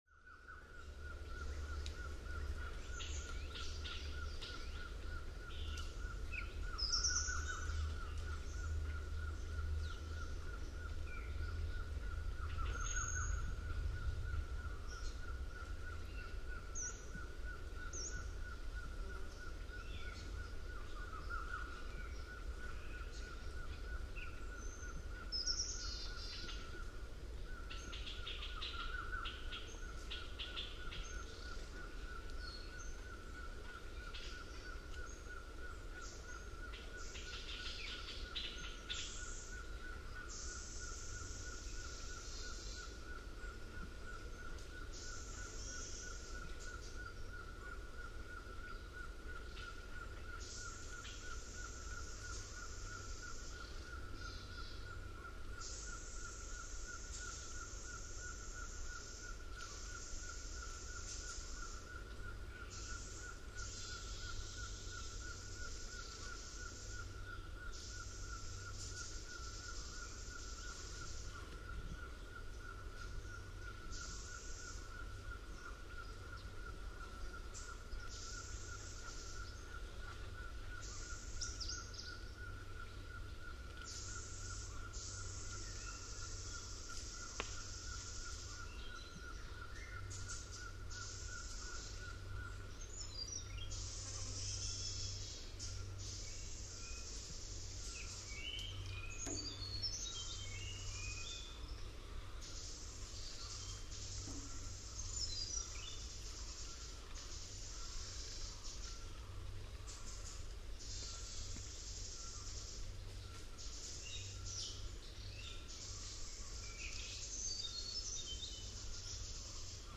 Thailand Jungle
Tune into this episode where we take you on a journey deep into the jungle of Thailand.
Thailand-Forest.mp3